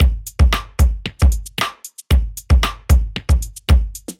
Loops » Disco Beat 114 bpm
描述：A disco drum beat loop
标签： 114bpm Disco Drums Loop DiscoBeat Beat
声道立体声